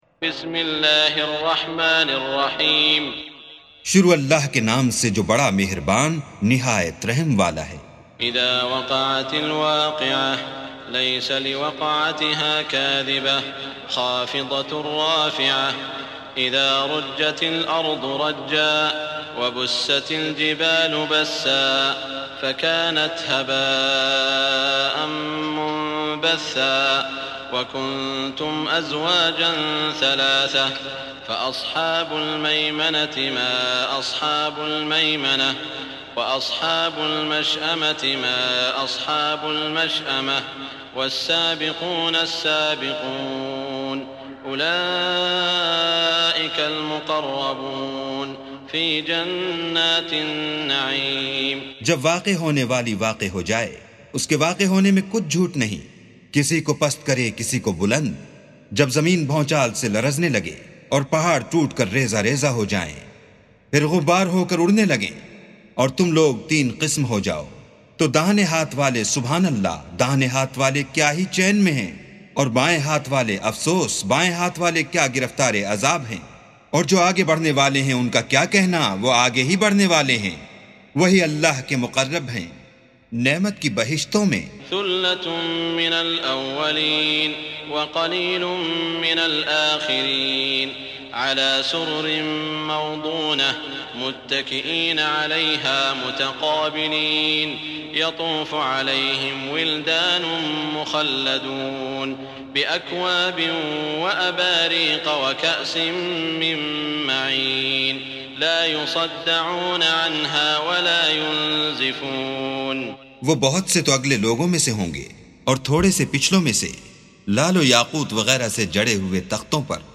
سُورَةُ الوَاقِعَةِ بصوت الشيخ السديس والشريم مترجم إلى الاردو